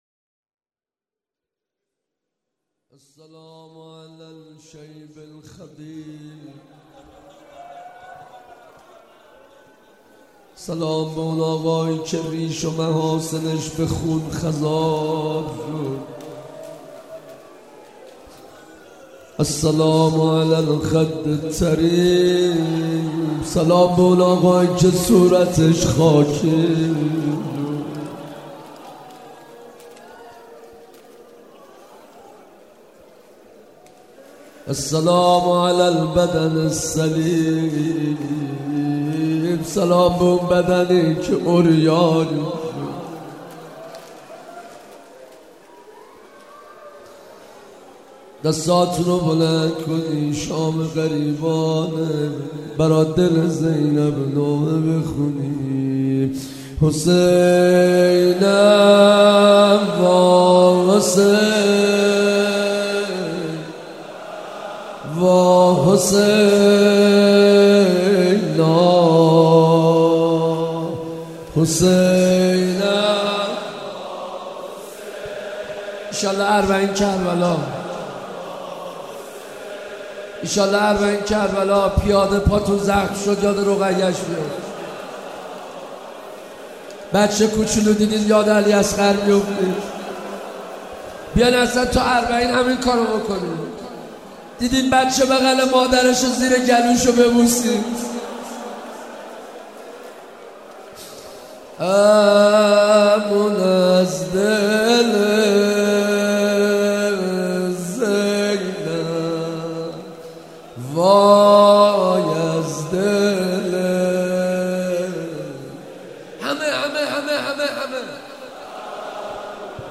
روضه حضرت اباعبدالله الحسین